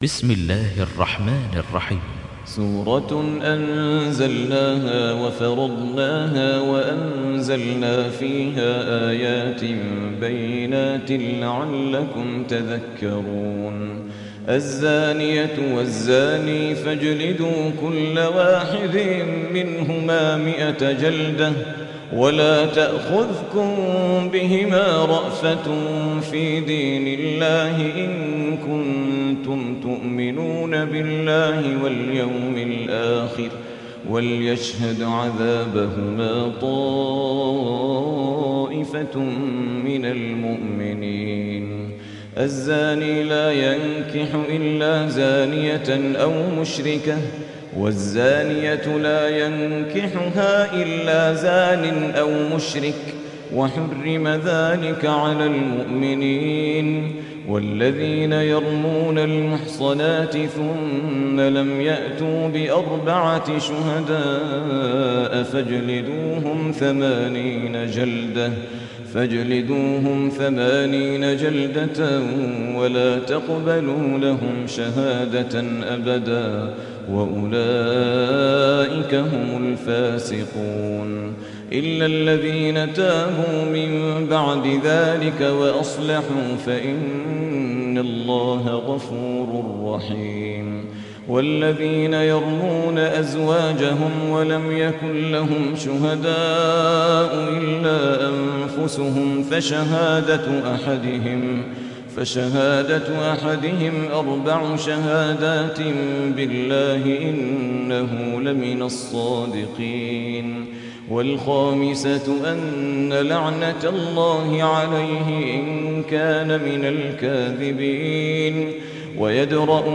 Riwayat Hafs
Riwayat Hafs an Asim